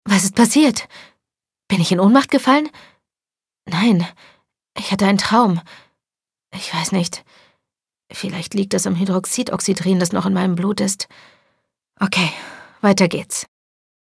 In der deutschen Fassung